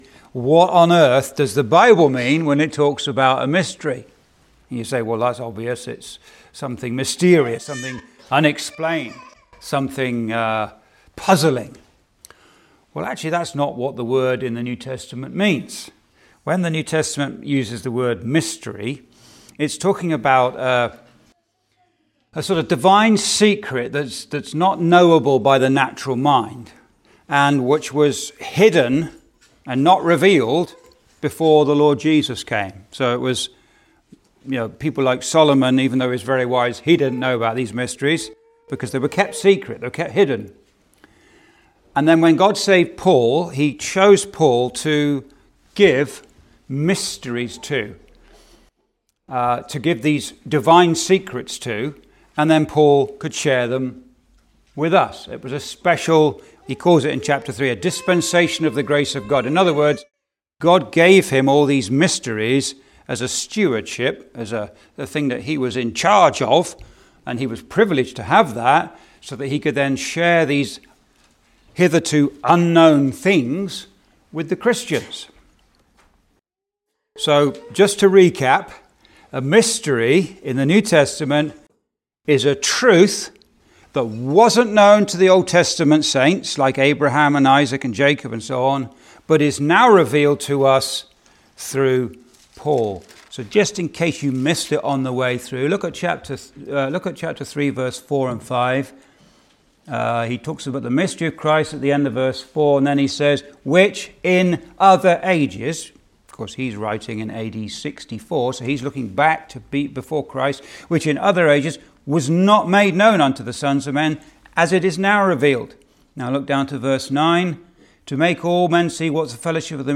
(Recorded in Thunder Bay Gospel Hall, ON, Canada on 13th Nov 2025)